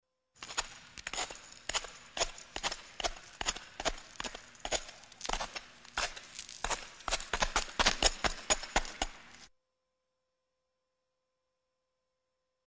Звуки катания на коньках
Человек идет на коньках по льду к нам, а потом удаляется от нас бегом